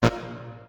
scpcb-godot/SFX/Radio/Buzz.ogg at e4012f8b7335c067e70d16efd9e1b39f61021ea4
Buzz.ogg